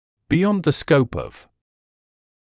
[bɪ’jɔnd ðə skəup ɔv][би’йонд зэ скоуп ов]за рамками; за пределами